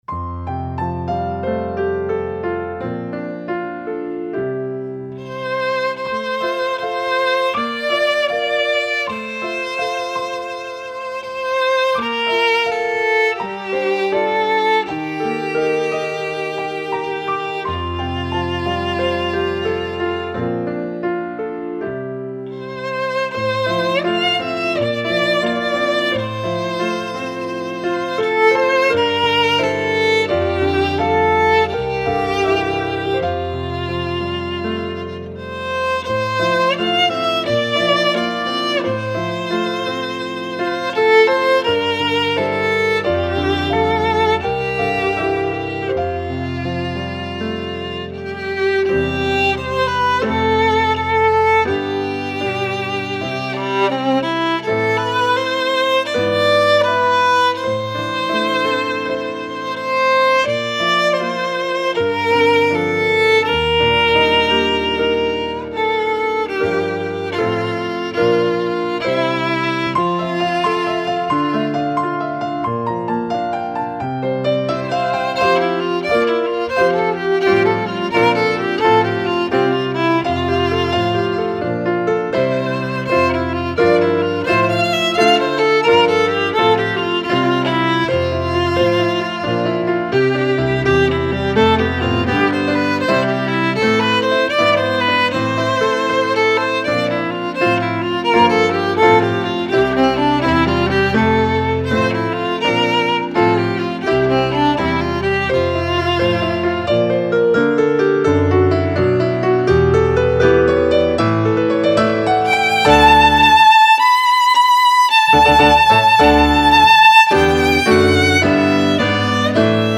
Intermediate Violin Solos for Thanksgiving